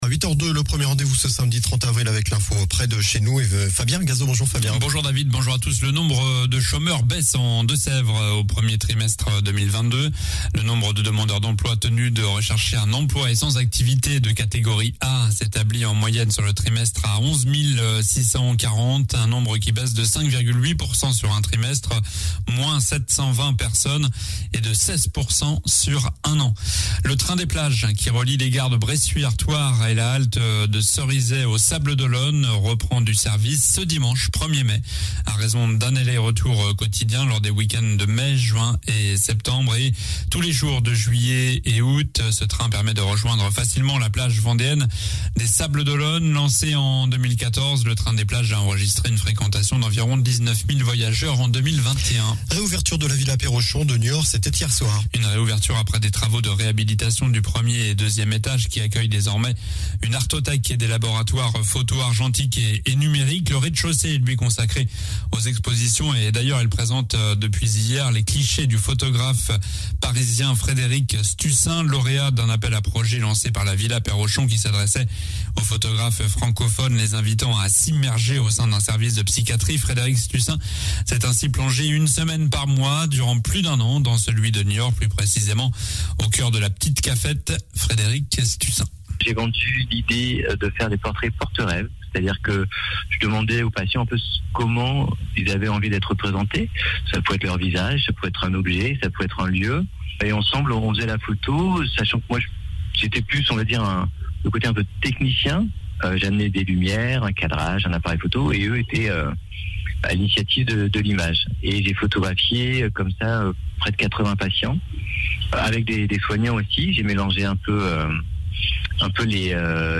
Journal ddu samedi 30 avril (matin)